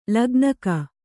♪ lagnaka